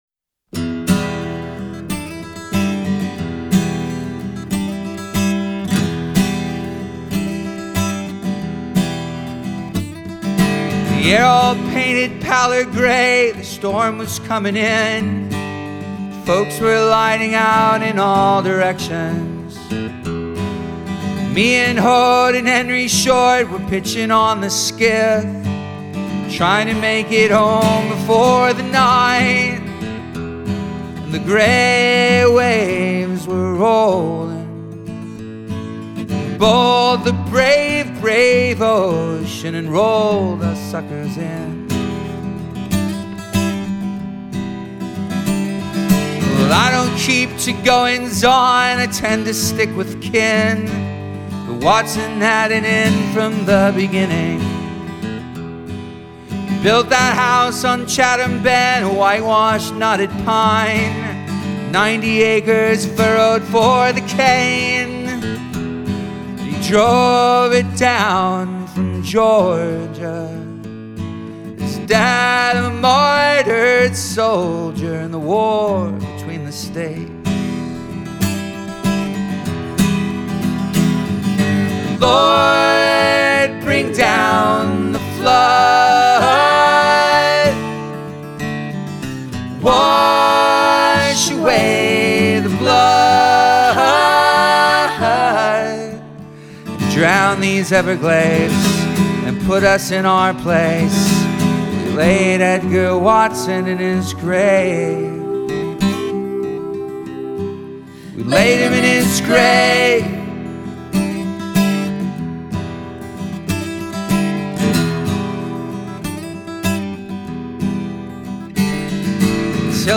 Style: Indie Folk